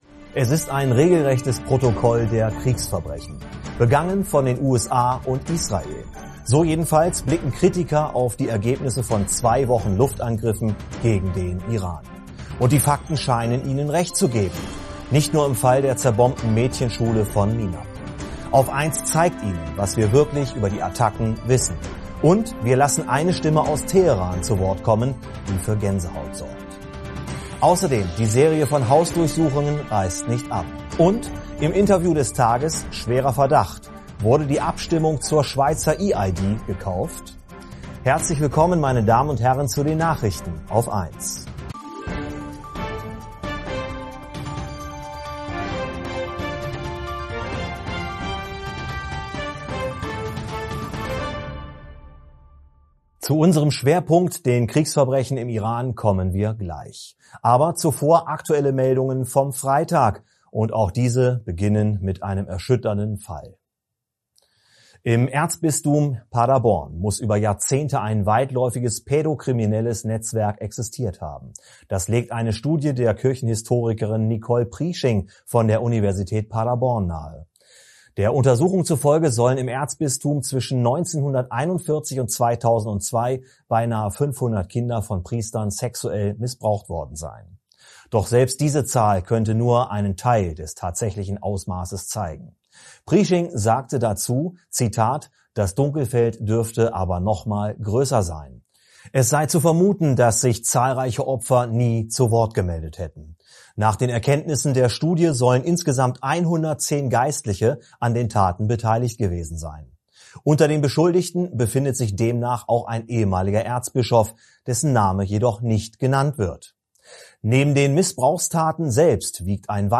AUF1 zeigt Ihnen, was wir wirklich über die Attacken wissen. Und wir lassen eine Stimme aus Teheran zu Wort kommen, die für Gänsehaut sorgt.
+ Und: Im Interview des Tages: Schwerer Verdacht: Wurde die Abstimmung zur Schweizer E-ID gekauft?